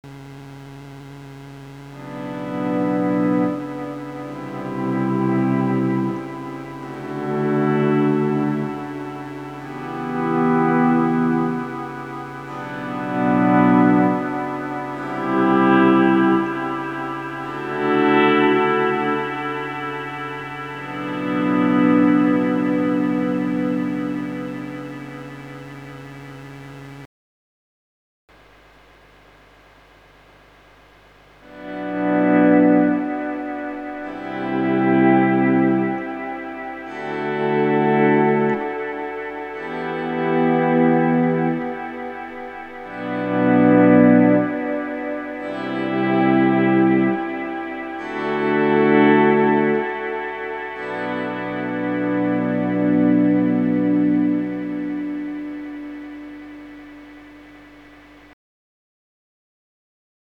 Capturing a good recording of an Echorec drum delay unit can be a challenging task as the they exhibit a relatively poor signal to noise ratio in comparison to modern digital delay units.
binson_earth_loop_noise_elimination.mp3